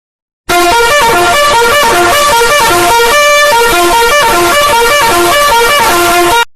Anime Alarm.mp3